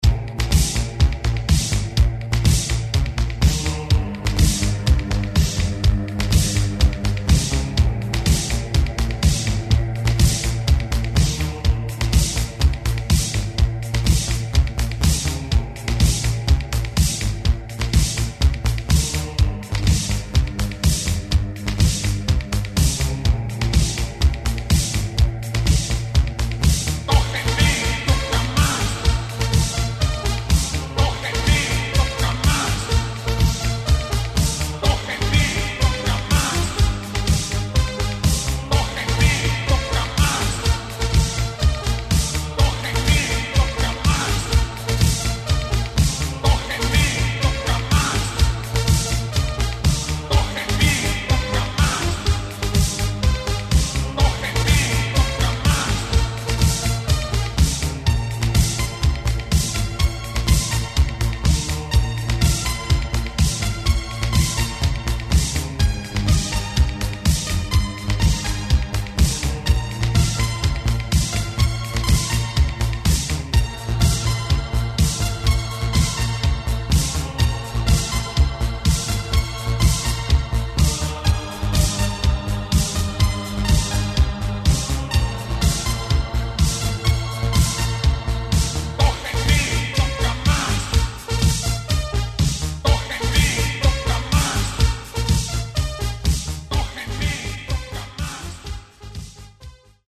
Большая просьба опознать зарубежную песню Italo Disco.